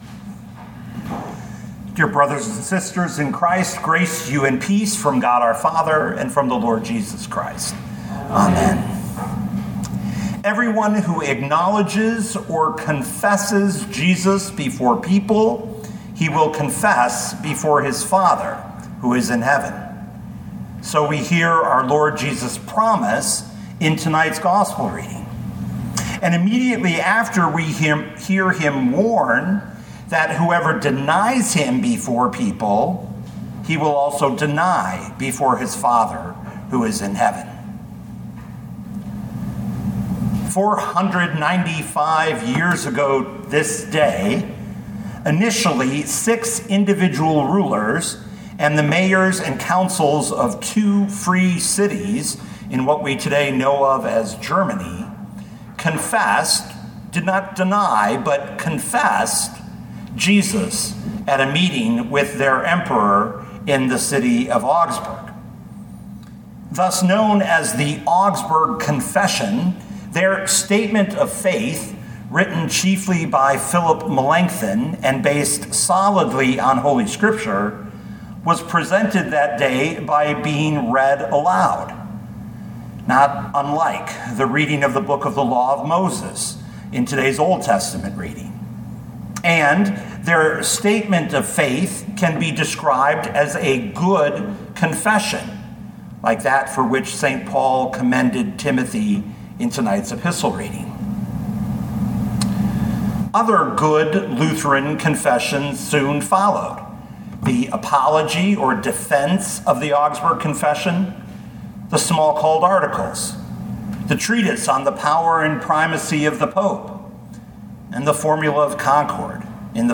2025 Matthew 10:26-33 Listen to the sermon with the player below, or, download the audio.